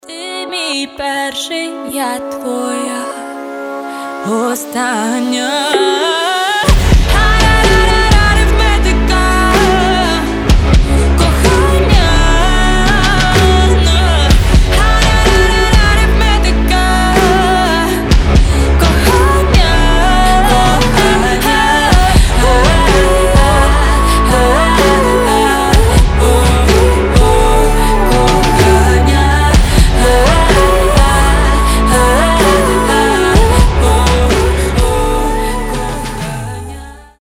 • Качество: 320, Stereo
женский голос